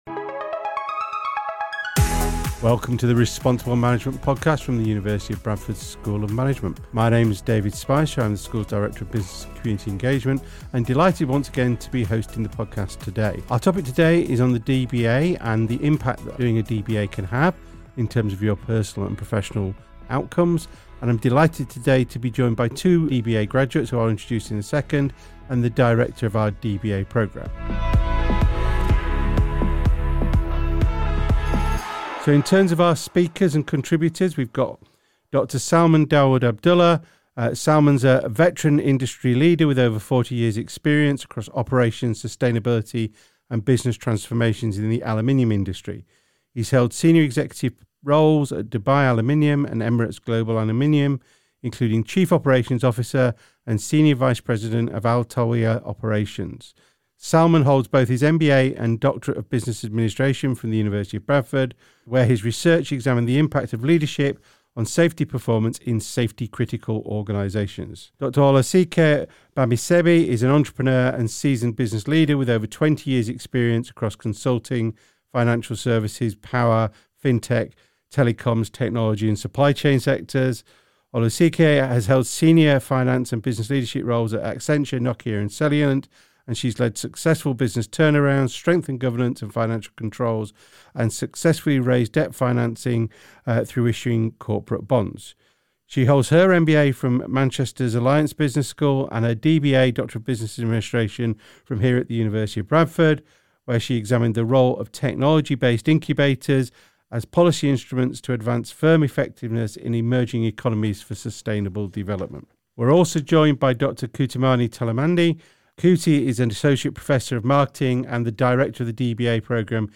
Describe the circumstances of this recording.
Conversation focuses on our DBA programme at the University of Bradford.